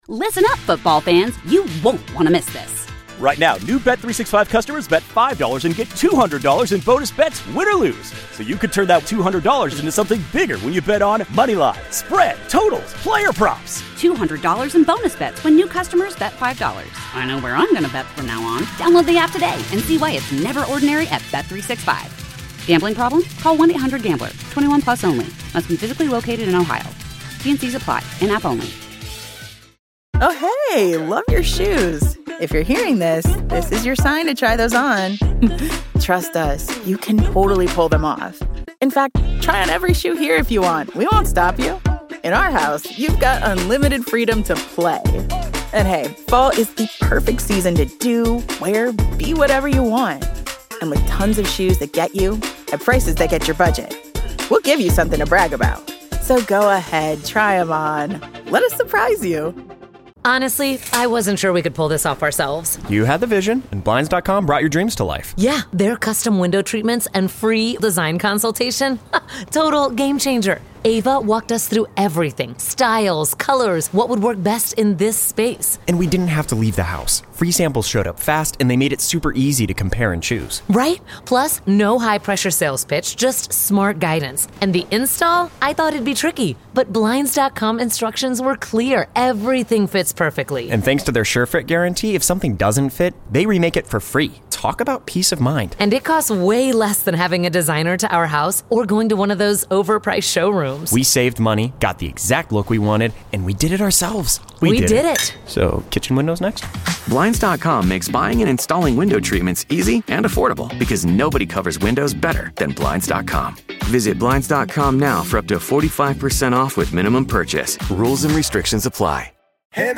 Matt Quatraro, manager of the Royals joined to talk about Bobby Witt and Michael Wacha's injury along with the wild card race.